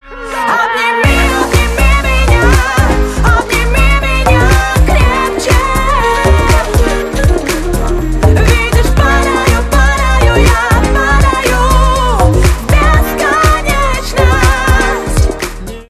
• Качество: 128, Stereo
поп
громкие
женский вокал